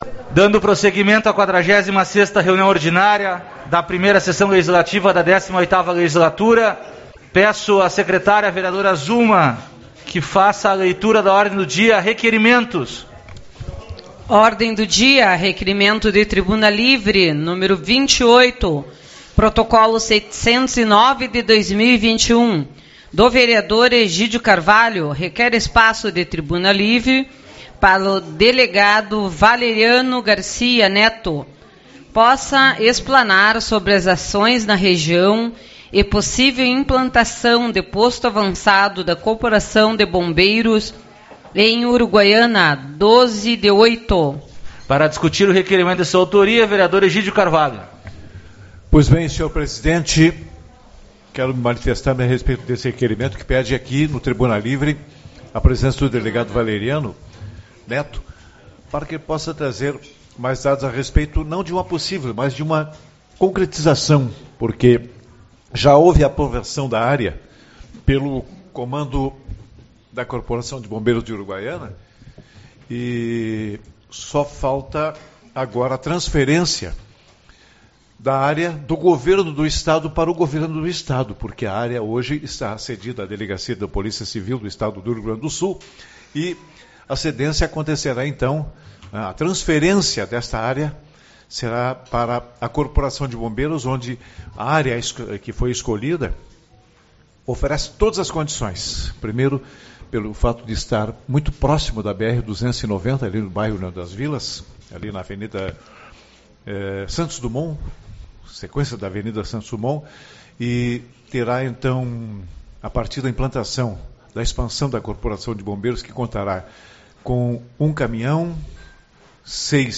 13/07 - Reunião Ordinária